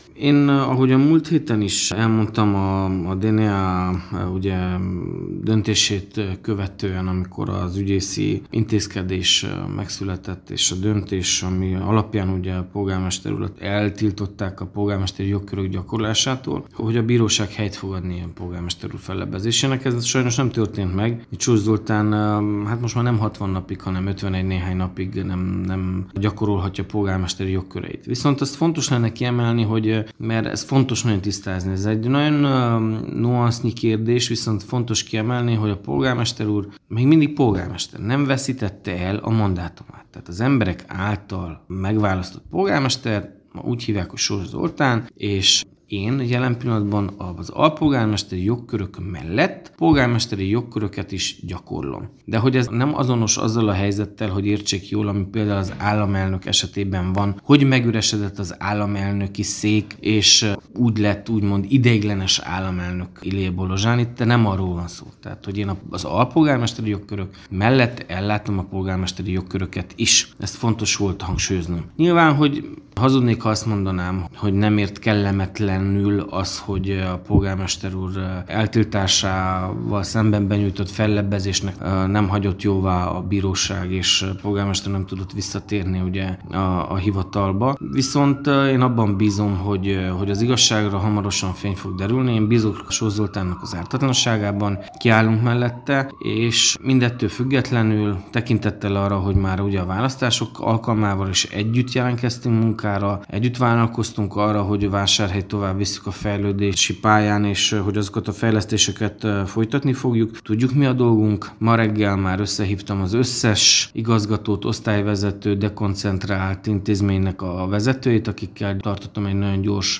A kialakult helyzetről, annak hátteréről és a következő lépésekről kérdeztük Kovács Mihály Levente alpolgármestert, az RMDSZ marosvásárhelyi szervezetének elnökét.